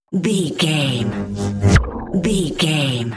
Sci fi pass by insect wings fast
Sound Effects
futuristic
pass by
sci fi